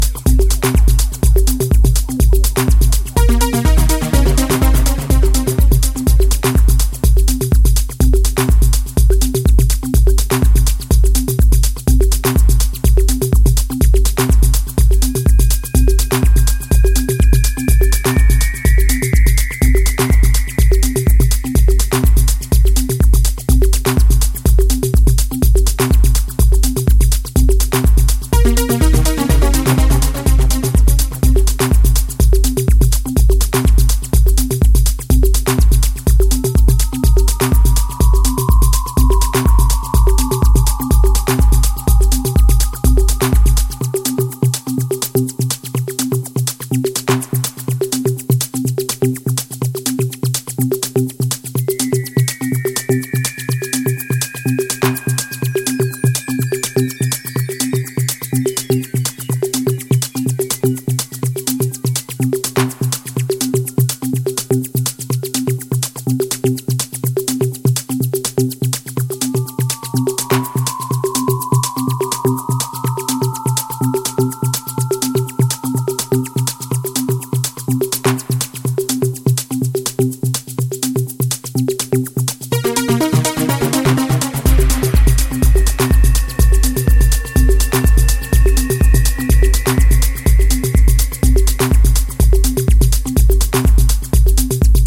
Deep Space Electro Techno tracks